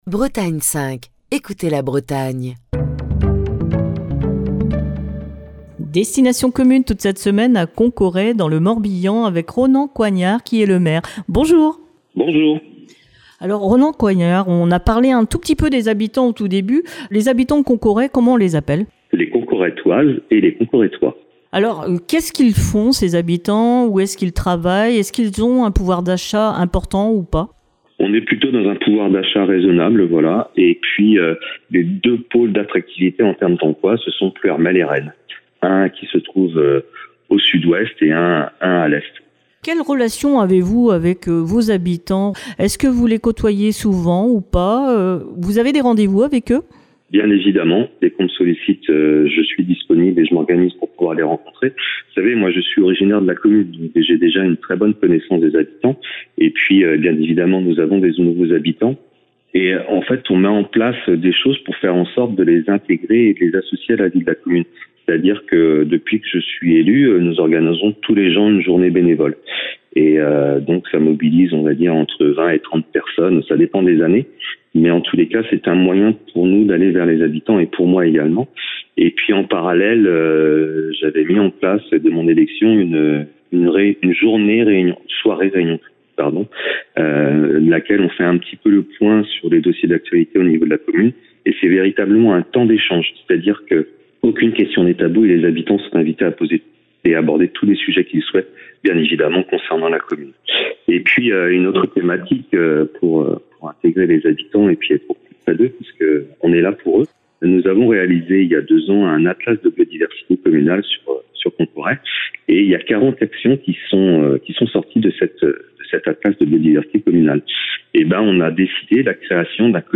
Émission du 30 janvier 2025.
en compagnie de son maire, Ronan Coignard.